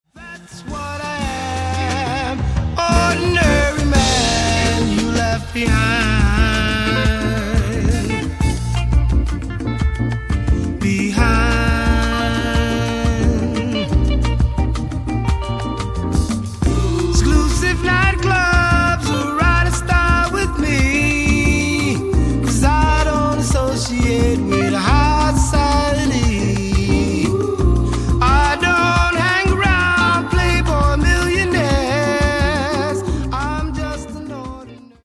Genre:   Latin Disco Soul